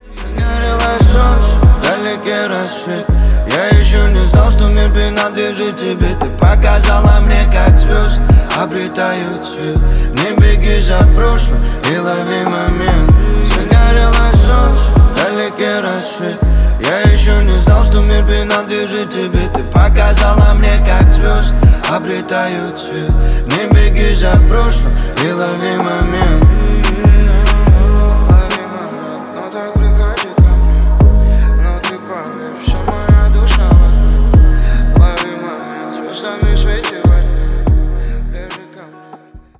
Рэп и Хип Хоп
спокойные